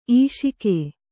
♪ īṣike